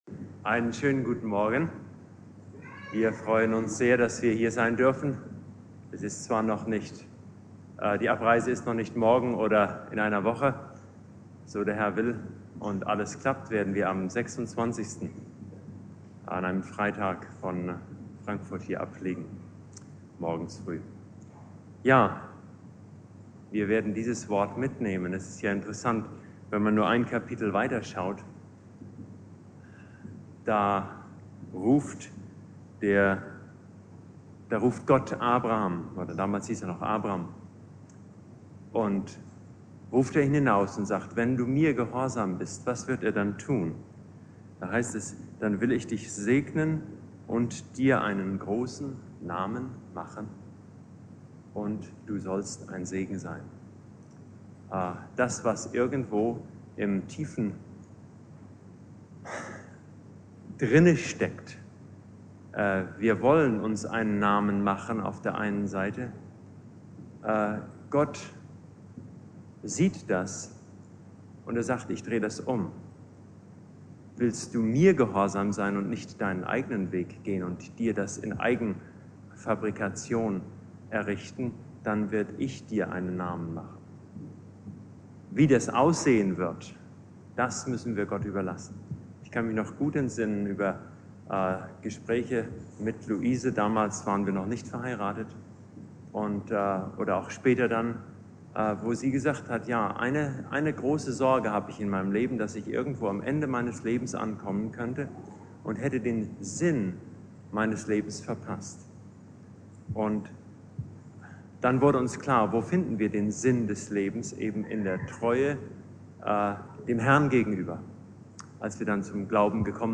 Predigt
Pfingstmontag Prediger